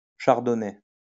Chardonnay (Fransızca telaffuz: [ʃaʁdɔnɛ]  (